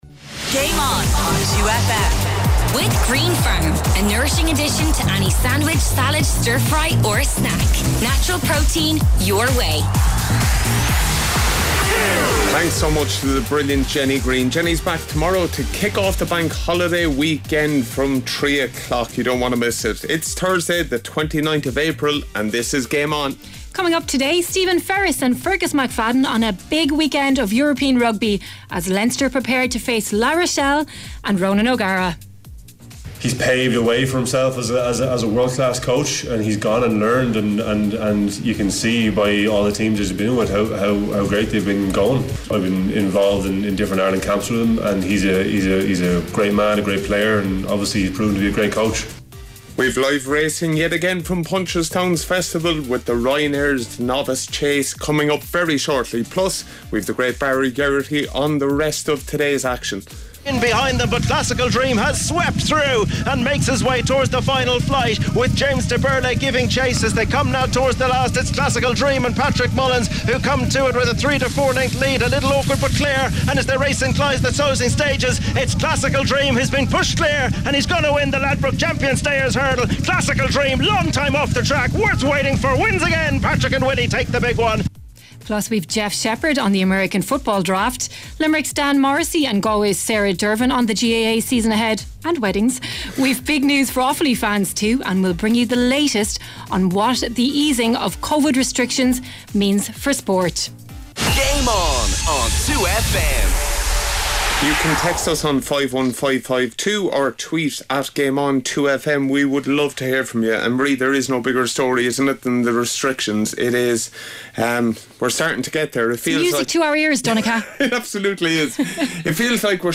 To achieve this noble goal, the radio operates with a more youthful approach in its broadcasts. The talk shows focus on lighter topics, and the music lineup features dynamic pop songs along with the freshest hits of the current music scene.